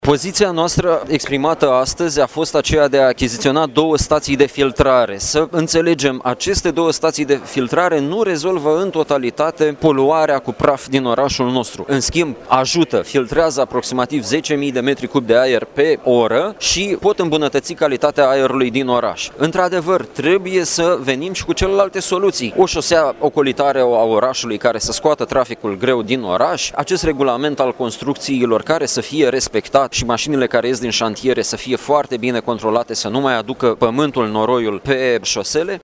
Preşedintele grupului liberal din Consiliul Local Iaşi, Eduard Boz, a precizat că  la 14 martie a fost declanşată procedura de infrigement pentru România deoarece în Iaşi, Braşov şi Bucureşti se depăşeşte pragul maxim admis de pulberi în suspensie în aer.